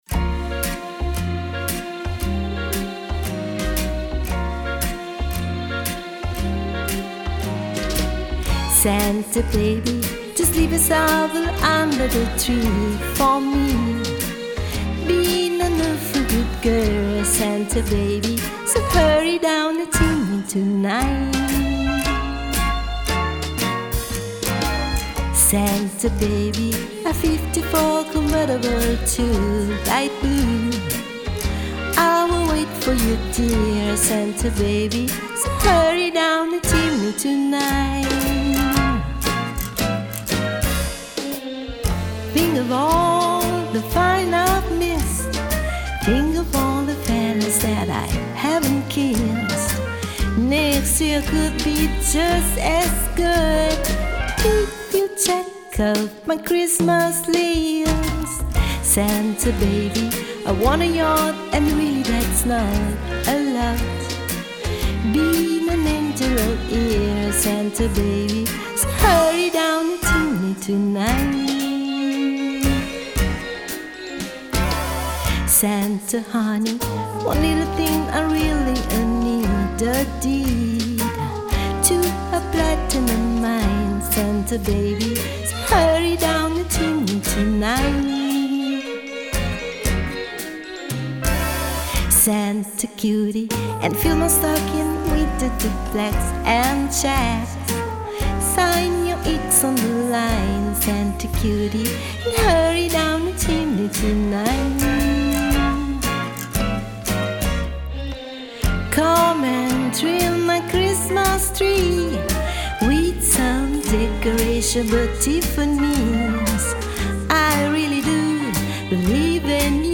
Guitar
Bass
Drum & Percussion
Piano
Voice